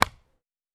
Ball Hit Normal.wav